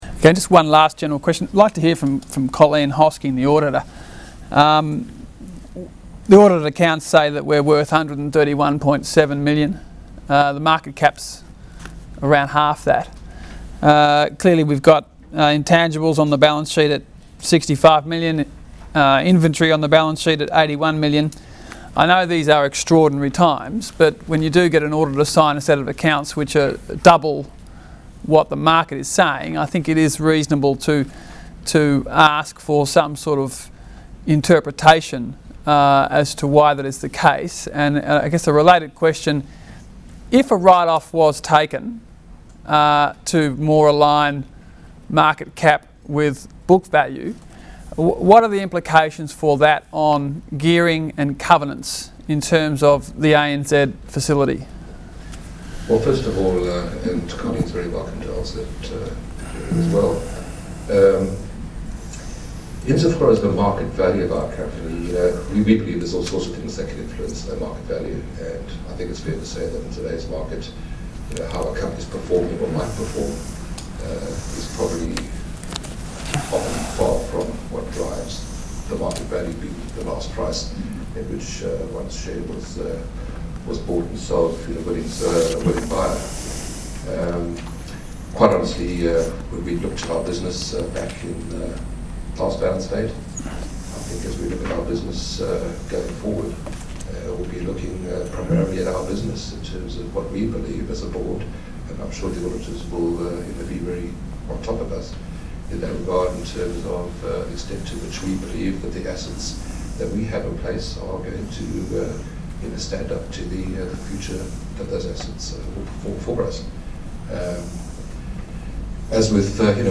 Below is a compilation of audio files from various AGMs over the years where auditors have been asked to comment on the accounts.
Housewares AGM, November 11, 2008